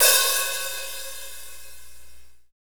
Index of /90_sSampleCDs/Northstar - Drumscapes Roland/DRM_Hip-Hop_Rap/HAT_H_H Hats x
HAT H H OH07.wav